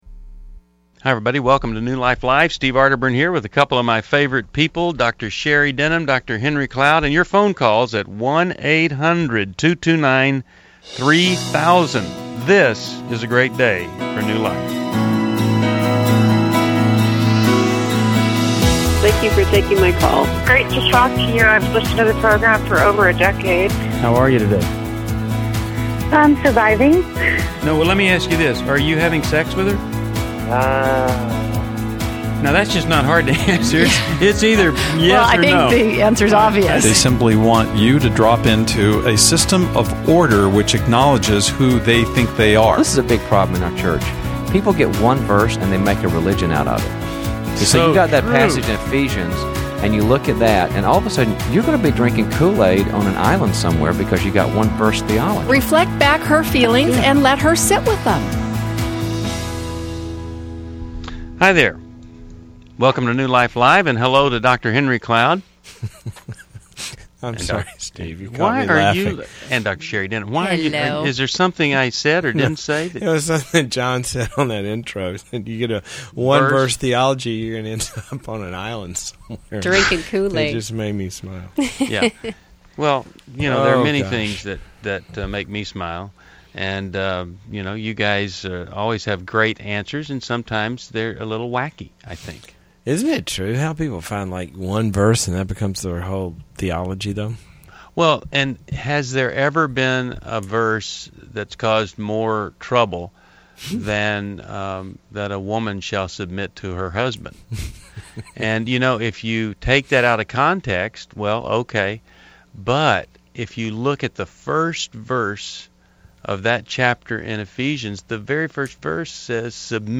Caller Questions: My boyfriend says he needs space after 1-1/2 years.